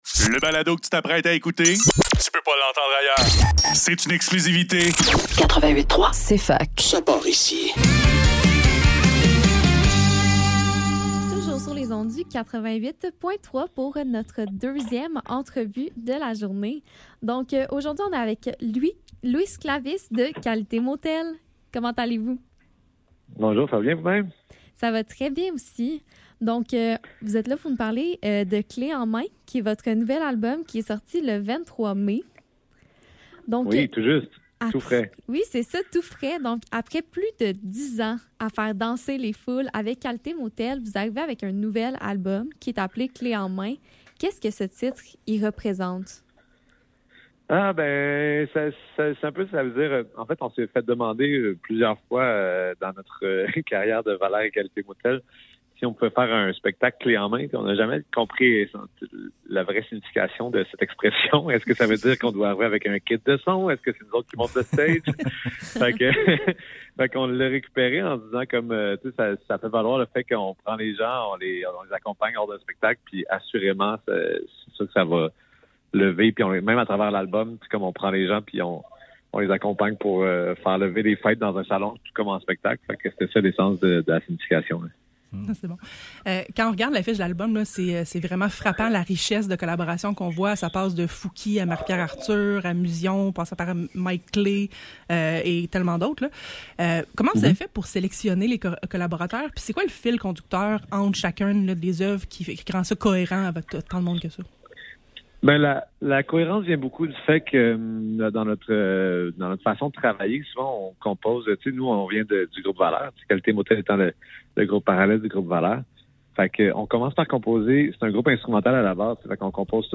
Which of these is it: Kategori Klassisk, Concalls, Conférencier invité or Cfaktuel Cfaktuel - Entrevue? Cfaktuel Cfaktuel - Entrevue